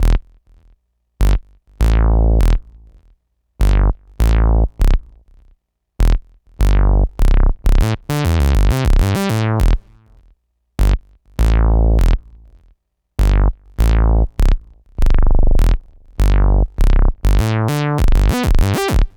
Bass 50.wav